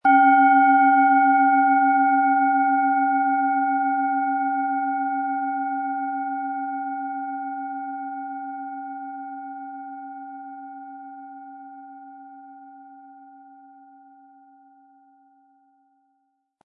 Planetenschale® Den ganzen Körper wahrnehmen & Wach und frisch fühlen mit Biorhythmus Körper, Ø 11,3 cm, 100-180 Gramm inkl. Klöppel
Der gratis Klöppel lässt die Schale wohltuend erklingen.
MaterialBronze